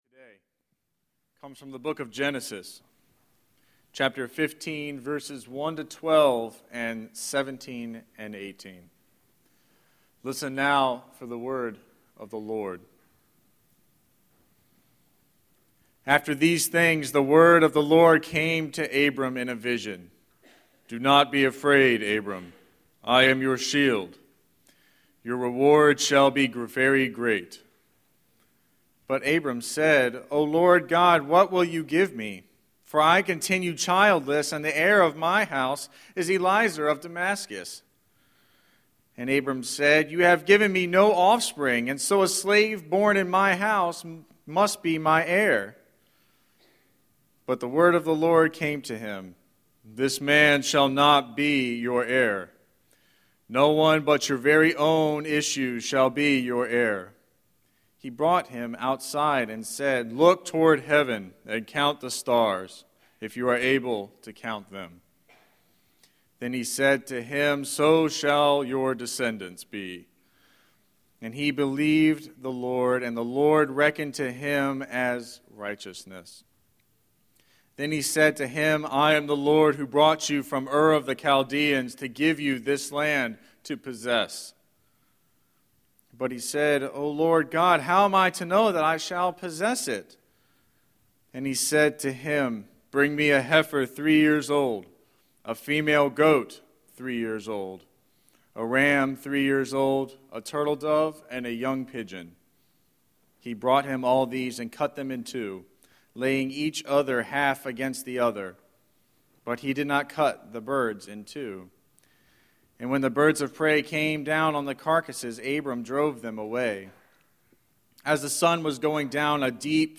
02-21-Scripture-and-Sermon.mp3